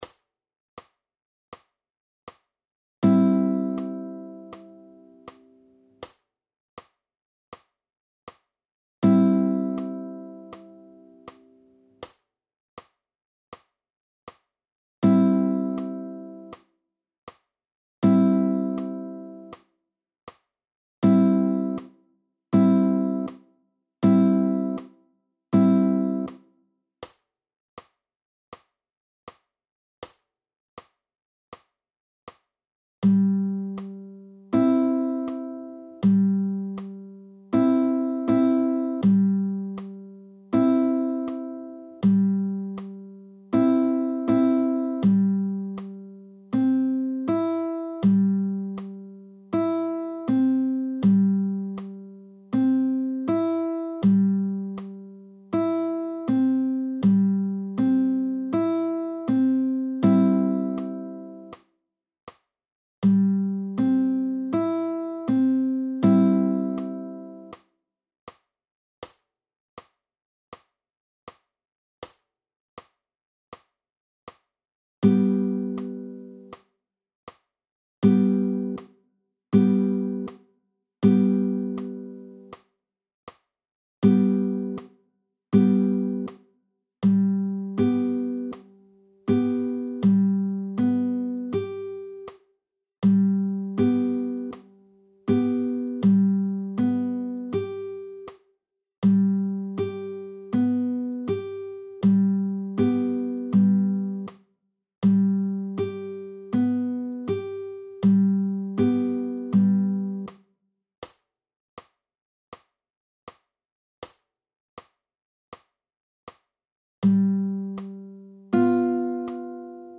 II.1) freier Anschlag mit p, i, m: PDF
Audio, 80 bpm: